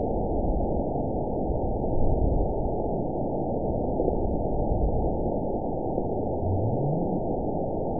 event 912623 date 03/30/22 time 12:43:49 GMT (3 years, 1 month ago) score 9.69 location TSS-AB04 detected by nrw target species NRW annotations +NRW Spectrogram: Frequency (kHz) vs. Time (s) audio not available .wav